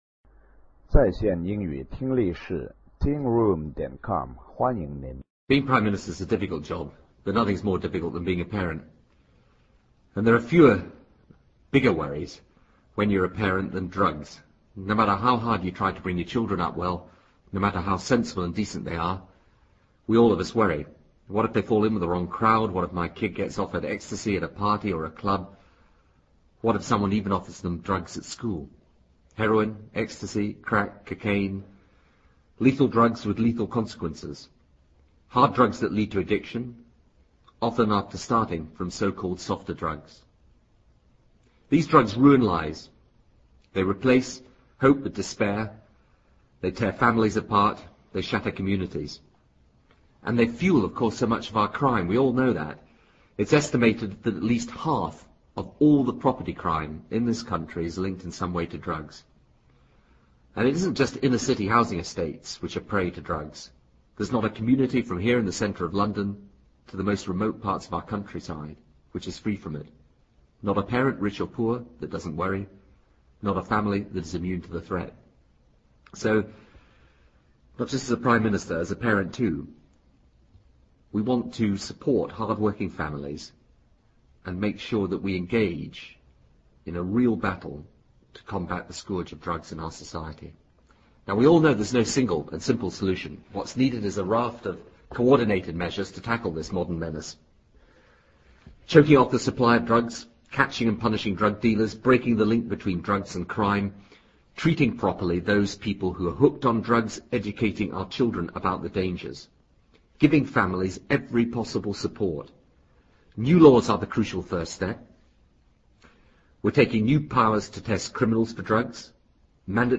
布莱尔首相演讲:Drugs[1] 听力文件下载—在线英语听力室